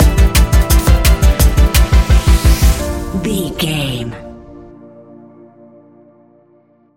Ionian/Major
A♯
electronic
techno
trance
synths
synthwave